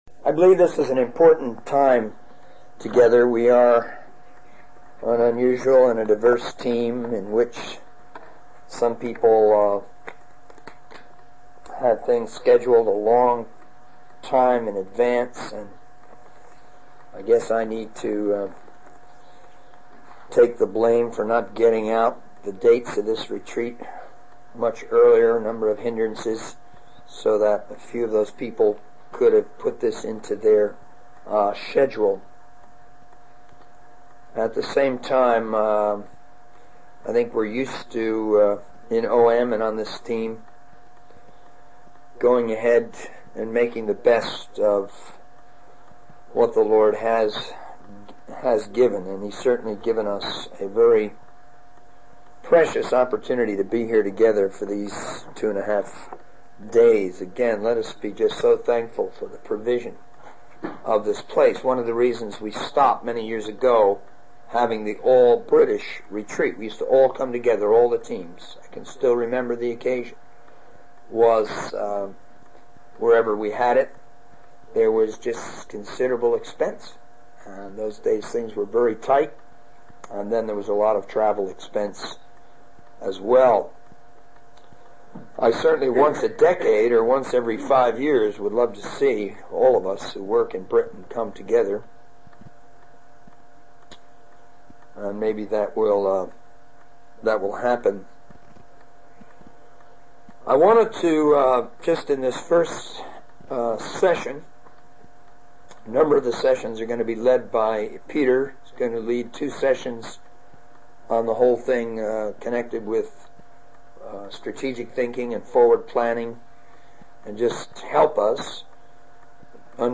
In this sermon, the speaker emphasizes the importance of having vision as a source of motivation.